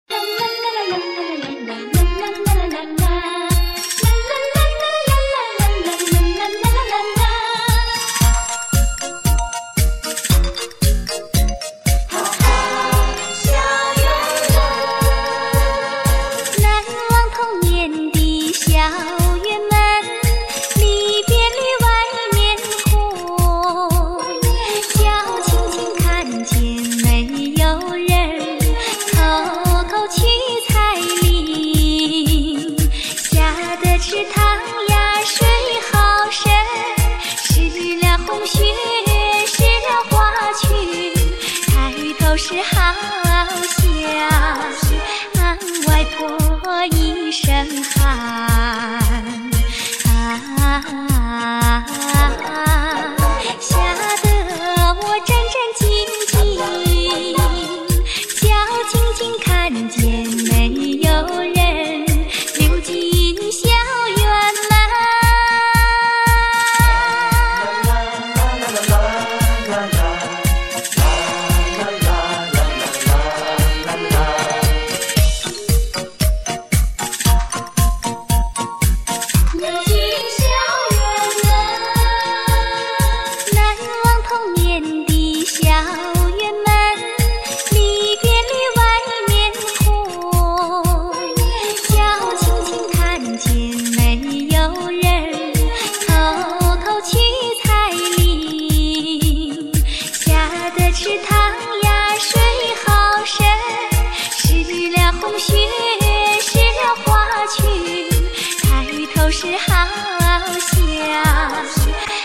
这首歌是从夜场交谊舞曲网上下载的截段试听，不知叫什么名字。